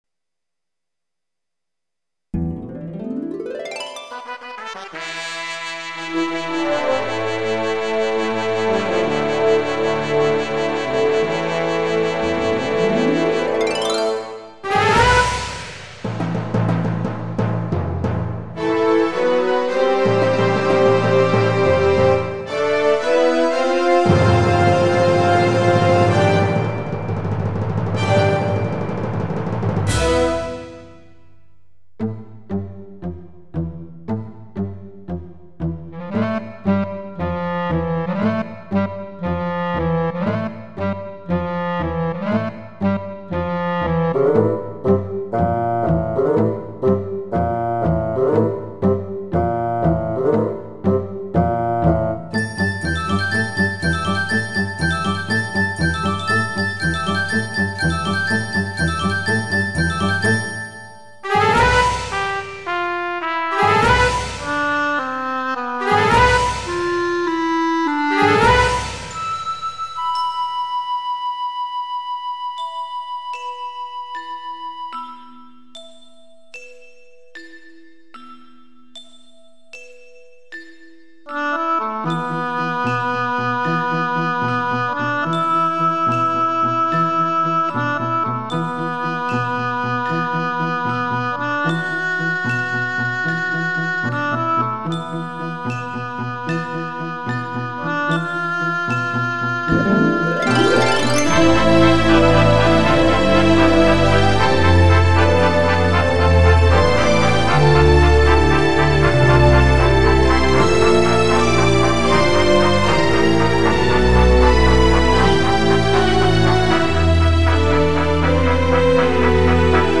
instrumental collection